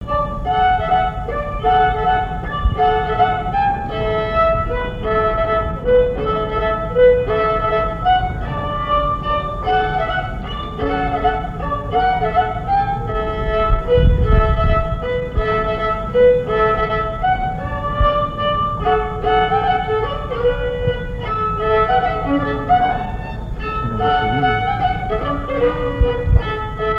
Mémoires et Patrimoines vivants - RaddO est une base de données d'archives iconographiques et sonores.
danse : varsovienne
Pièce musicale inédite